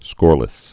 (skôrlĭs)